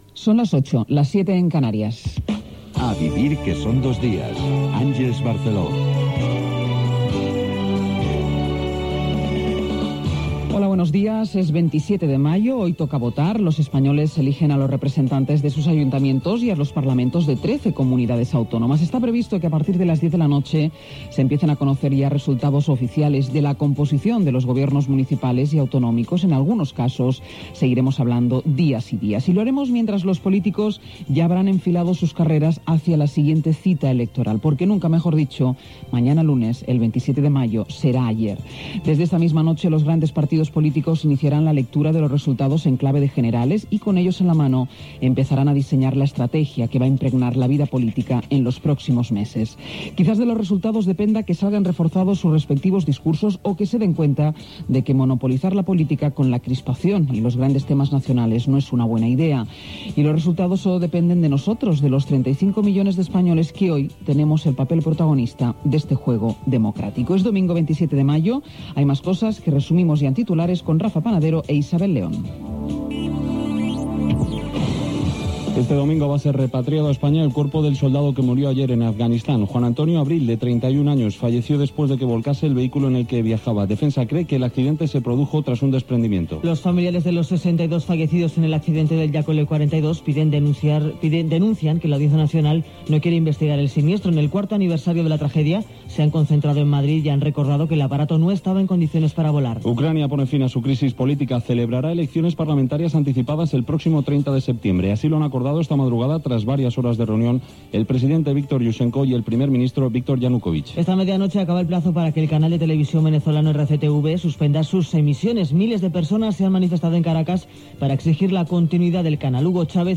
Hora, careta, data, dia de les eleccions municipals i autonòmiques a l'Estat espanyol, resum informatiu, esports, el temps. Publicitat. Les eleccions municipals i autonòmiques amb una connexió amb un col·legi electoral de Madrid, electors estrangers, consulta dels resultats per SMS o per Internet
Info-entreteniment